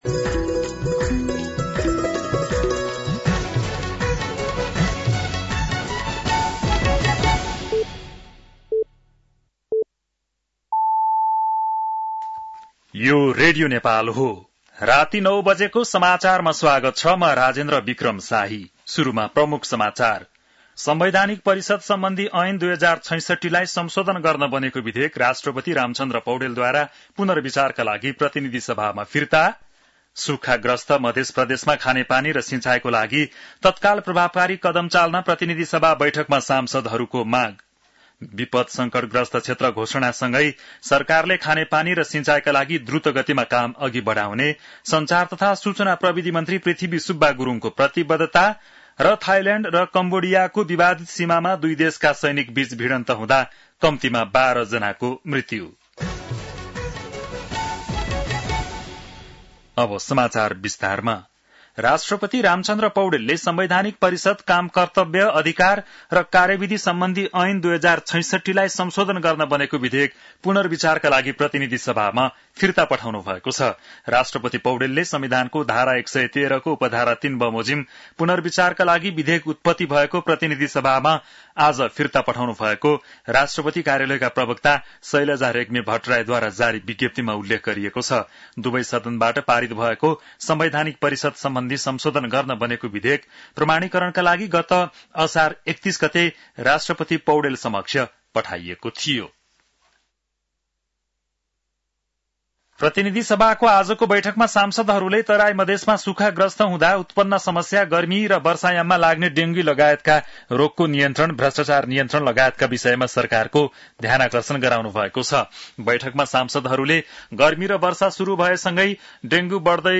बेलुकी ९ बजेको नेपाली समाचार : ८ साउन , २०८२
9-PM-Nepali-NEWS-4-8.mp3